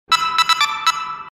Уведомление о получении сообщения Telegram и другие интересные звуки, которые вам пригодятся.
Telegram рингтон